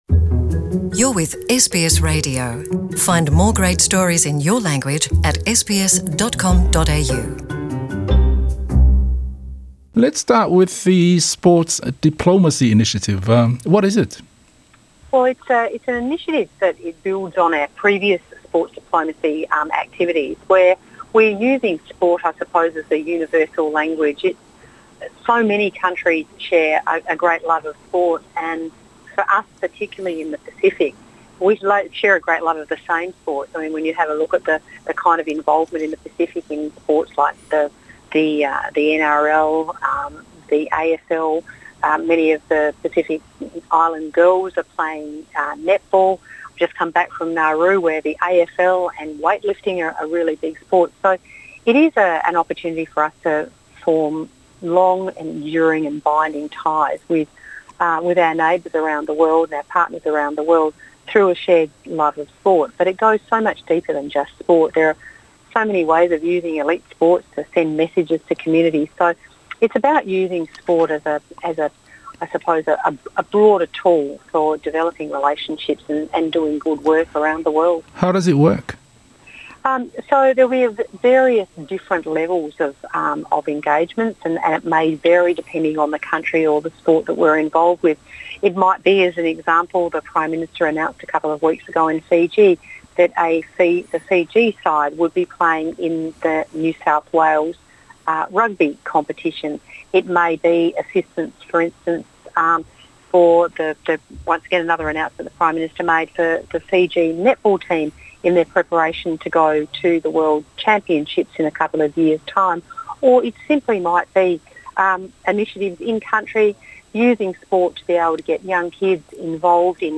Talanoaga ma le Minisita o mataupu i le Pasefika Senator Anne Ruston i galuega vaitau ma nofoaga saisai